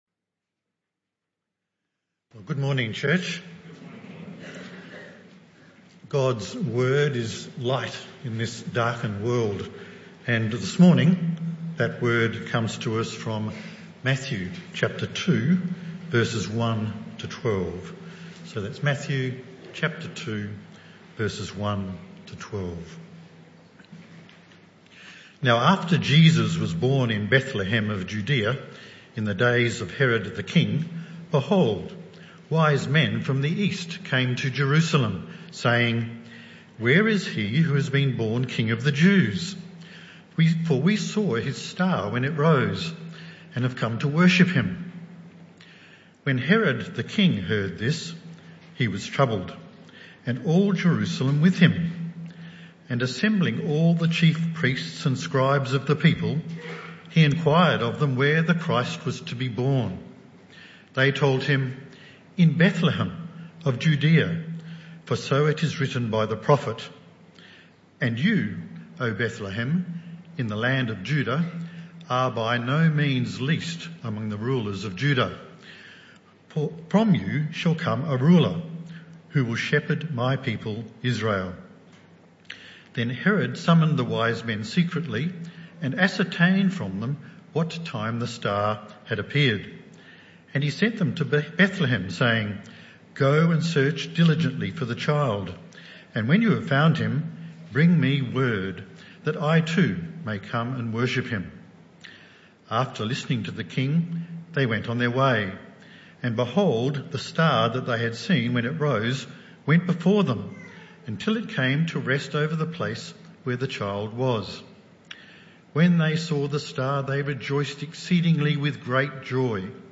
(Christmas 2019) | This talk was part of the AM Service series entitled Who Shall Be King? (Talk 2 of 4)